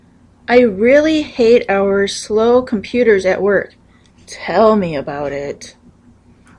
In this case it’s ironic.
I made a recording so you can hear the difference.